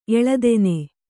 ♪ eḷadene